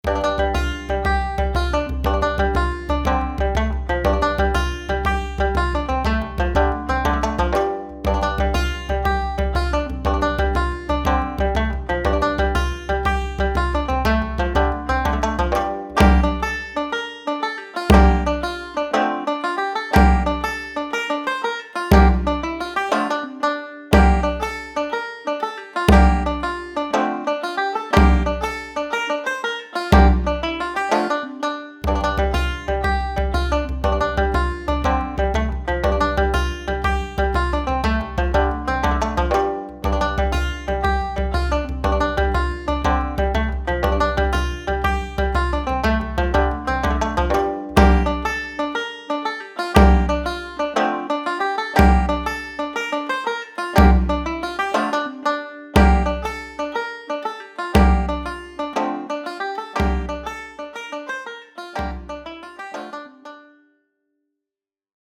Irish Jig Console: Play quietly | Stop